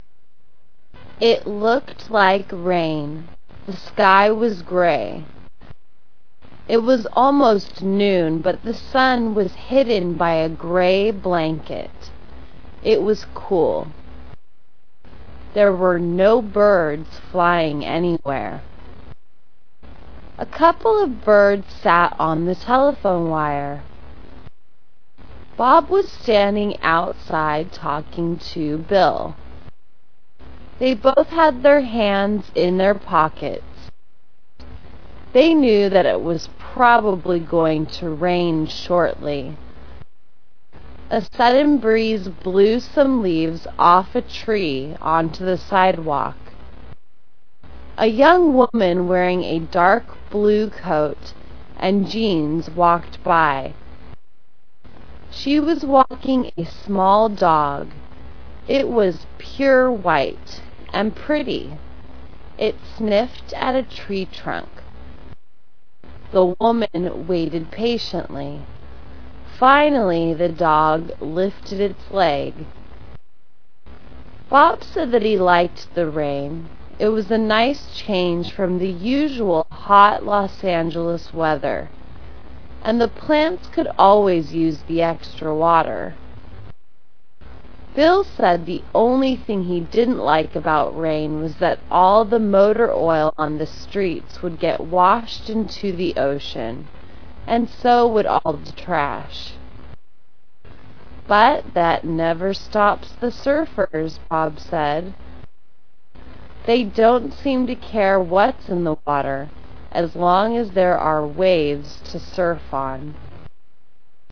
Slow  Stop audio